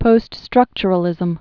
(pōststrŭkchər-ə-lĭzəm)